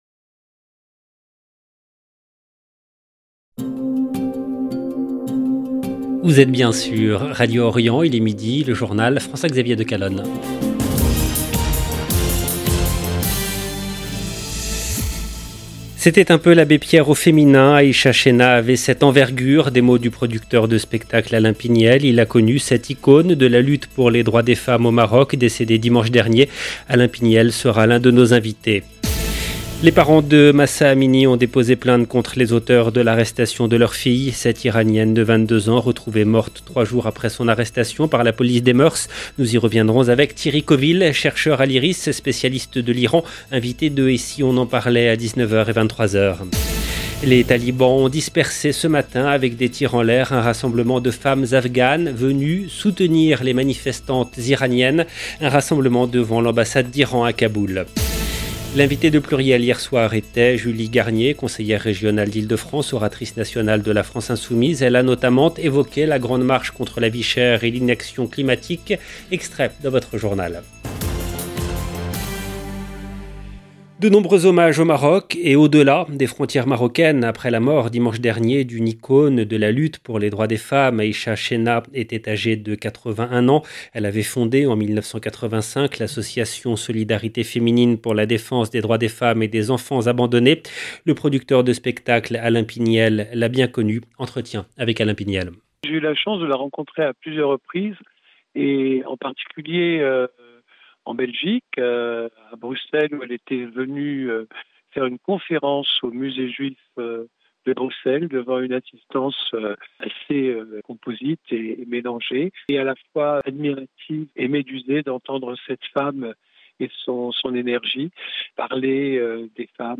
LE JOURNAL EN LANGUE FRANCAISE DE MIDI DU 29/09/22